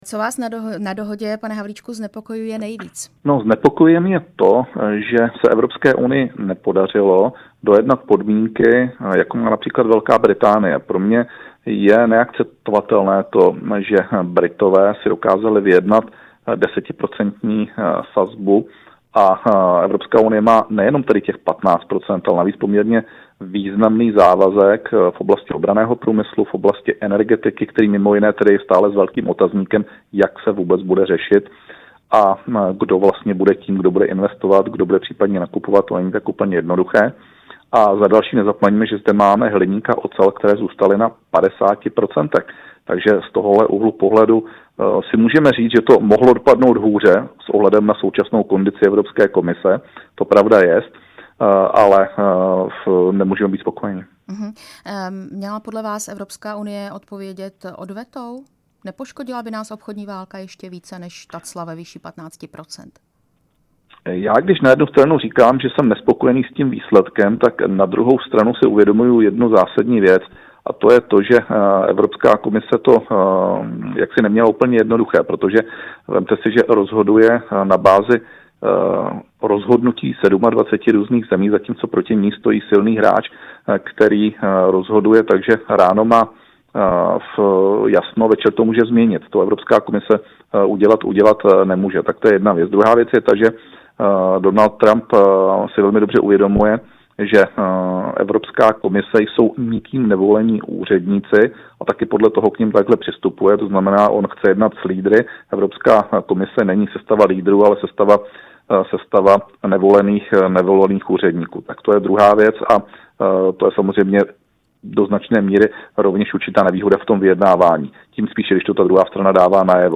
Rozhovor s místopředsedou hnutí ANO Karlem Havlíčkem